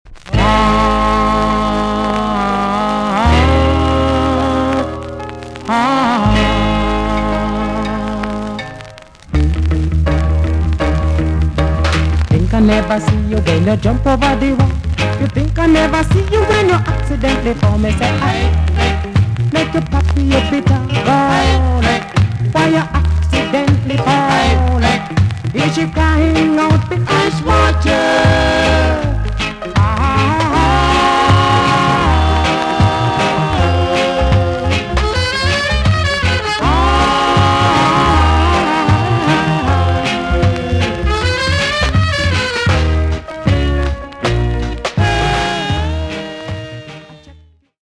VG ok 全体的にチリノイズが入ります。